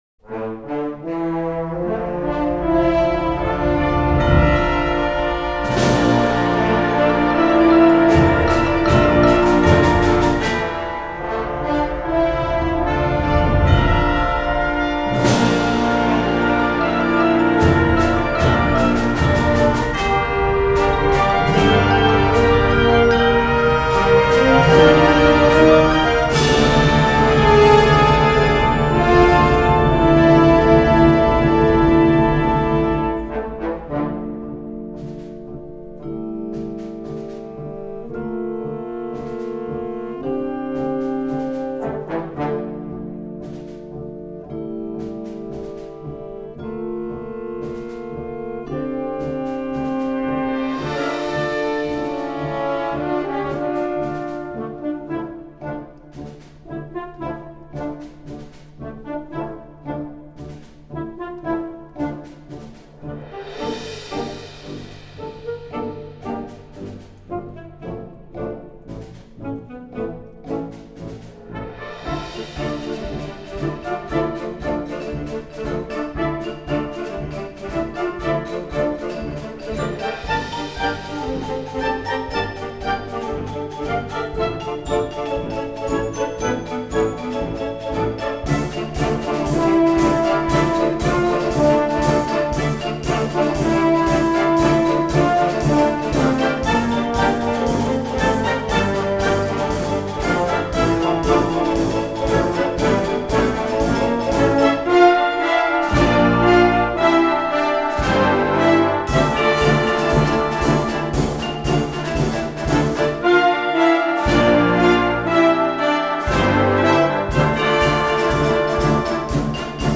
Konzertwerk für Jugendblasorchester
Besetzung: Blasorchester